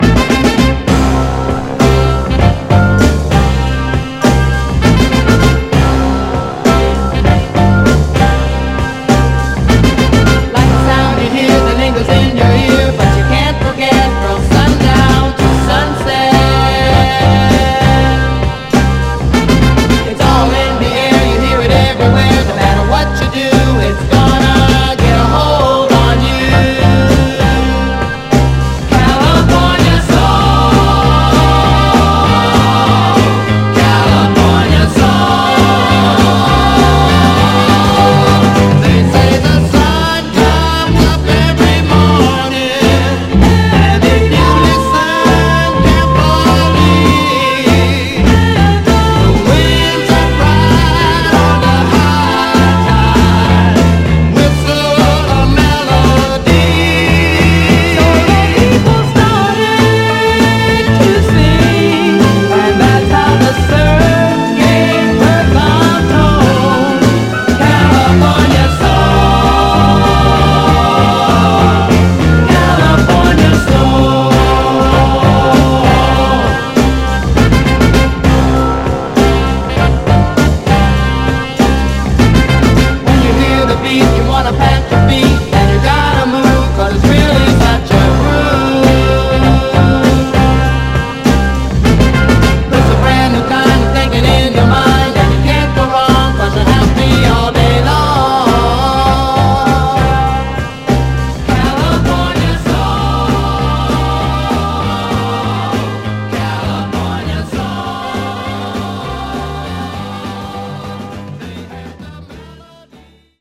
タフなヴァイナル・プレス。
盤はエッジ中心にごく細かいスレ箇所ありますが、グロスがありプレイ良好です。
※試聴音源は実際にお送りする商品から録音したものです※